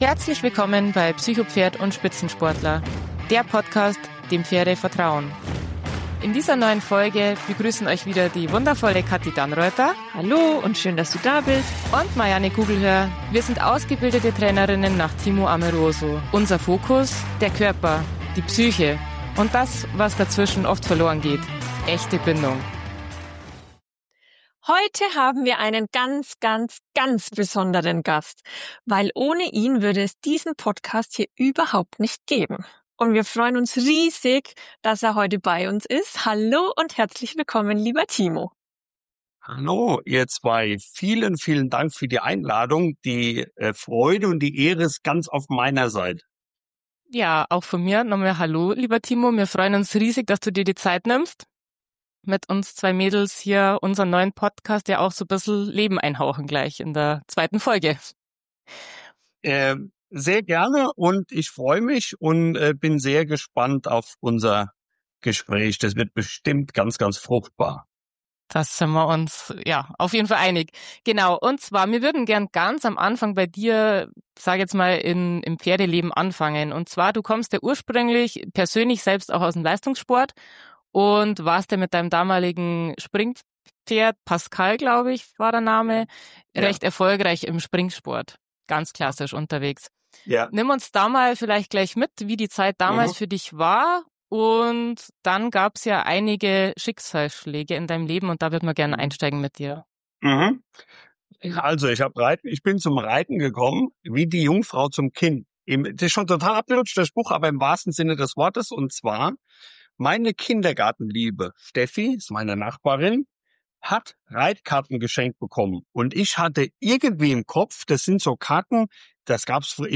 Beschreibung vor 7 Monaten In dieser Folge dürfen wir einen ganz besonderen Gast begrüßen